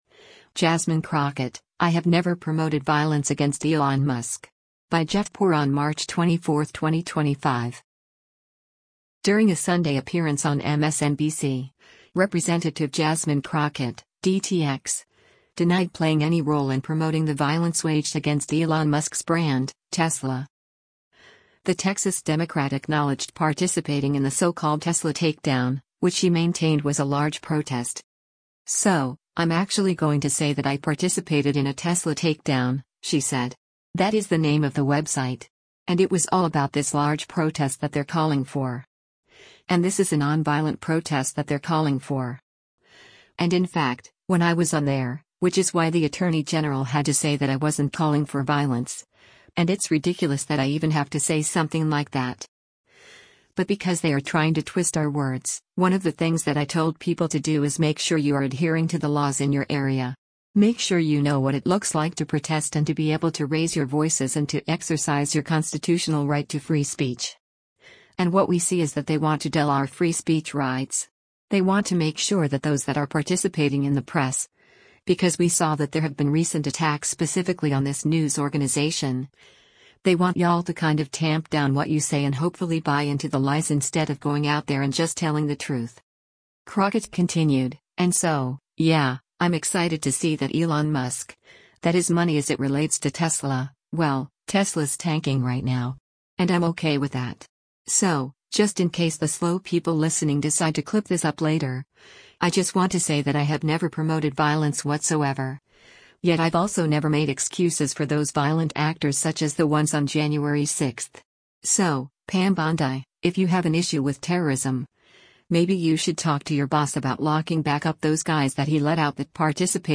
During a Sunday appearance on MSNBC, Rep. Jasmine Crockett (D-TX) denied playing any role in promoting the violence waged against Elon Musk’s brand, Tesla.